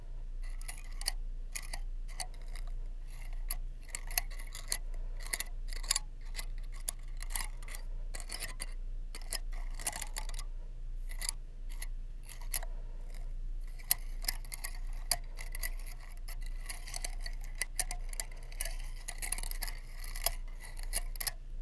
Matcha whisk.wav